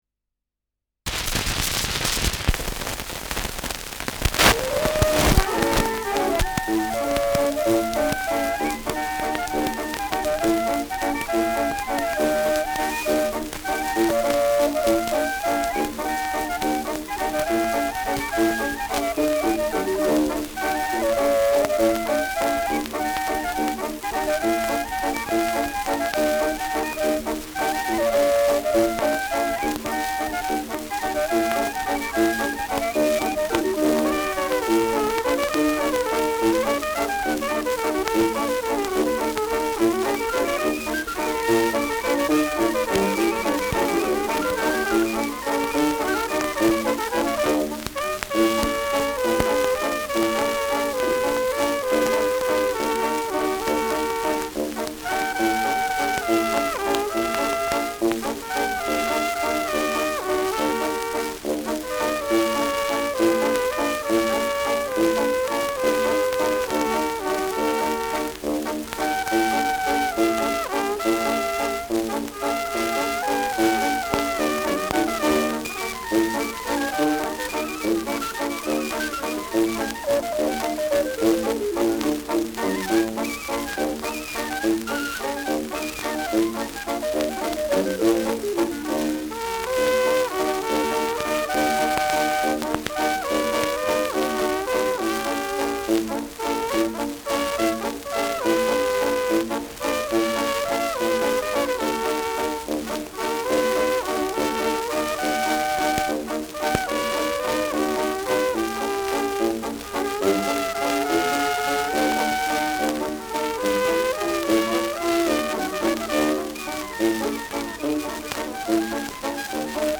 Schellackplatte
leichtes Rauschen
Dachauer Bauernkapelle (Interpretation)
[München] (Aufnahmeort)